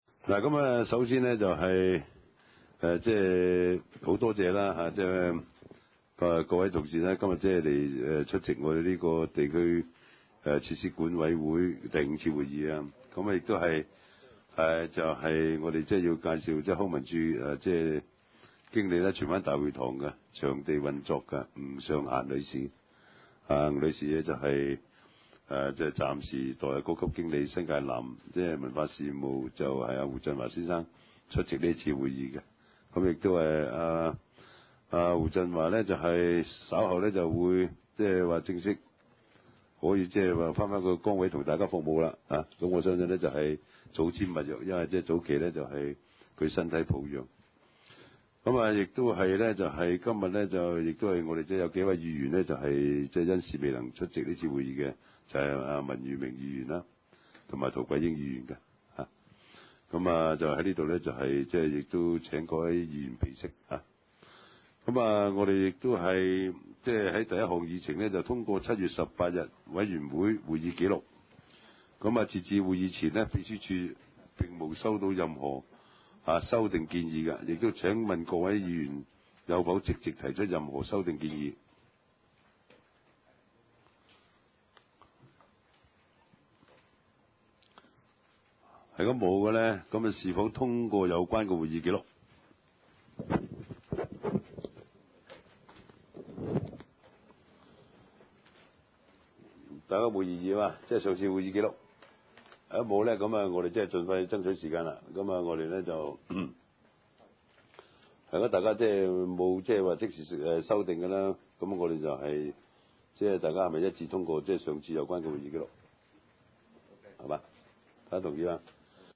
地區設施管理委員會第五次會議
荃灣民政事務處會議廳